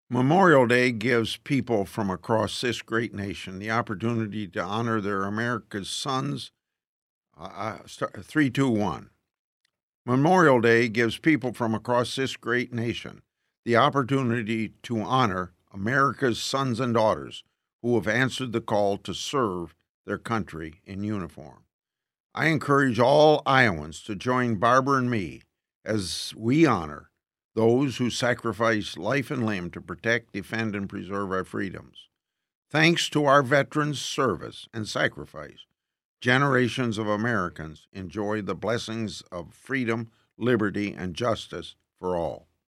PSA, 5-21-15, Memorial Day.mp3